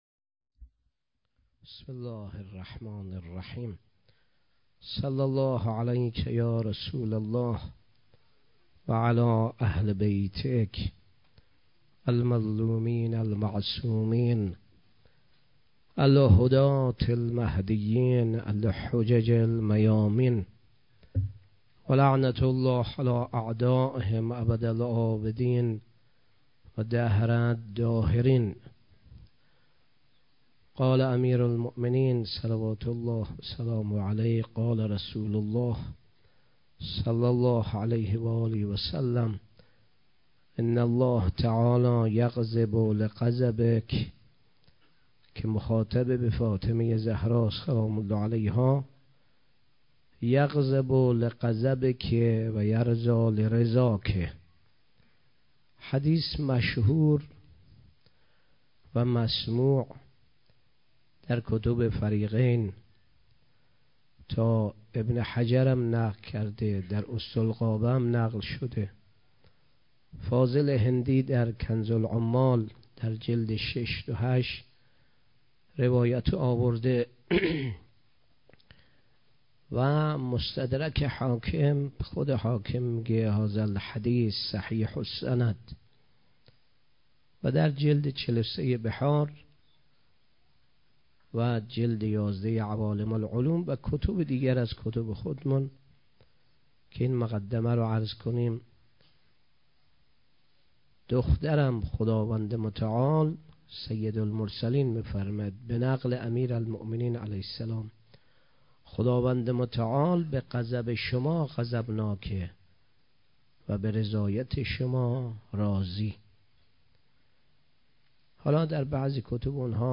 19 بهمن 97 - هیئت ام ابیها - سخنرانی